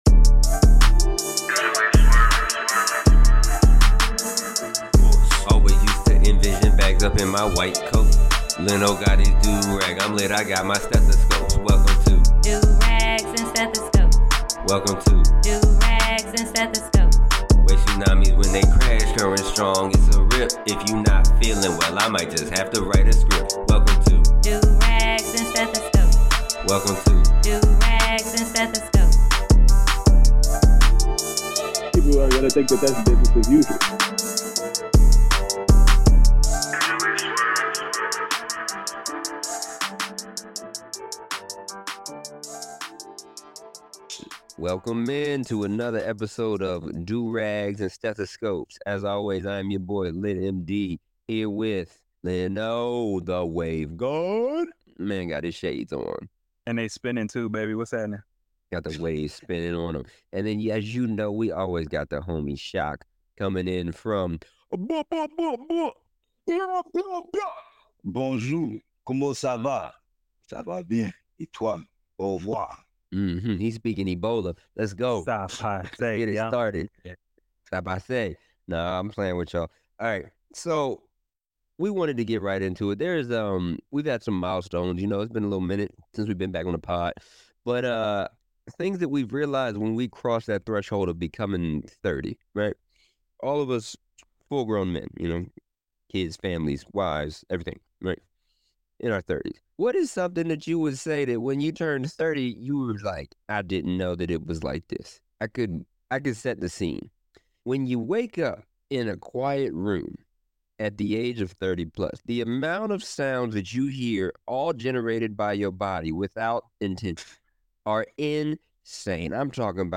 From Dreadlocks to Diabetes and Hip-Hop to Hypothyroidism Durags and Stethoscopes is a dialogue between 2 friends as they chronicle the everyday of being black in America.